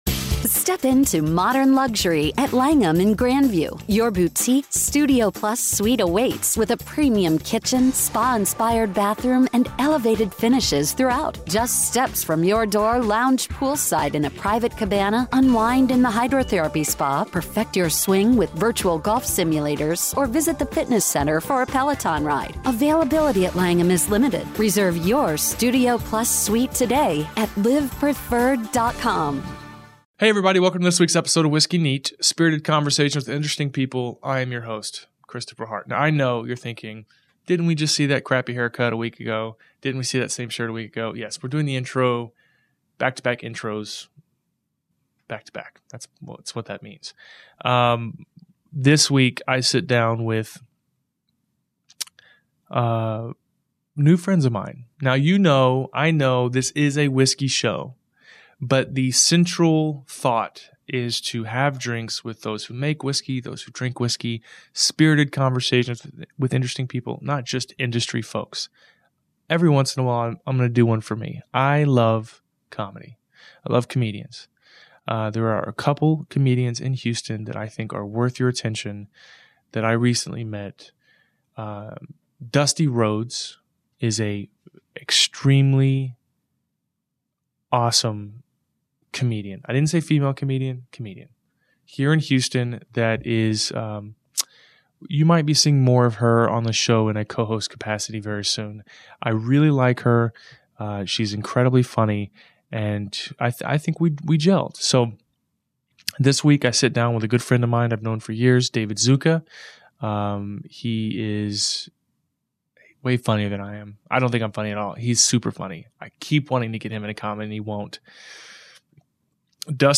The week following my first ever open Mic- I sit down with a couple of Local Standups for drinks and discussion around booze and Jokes.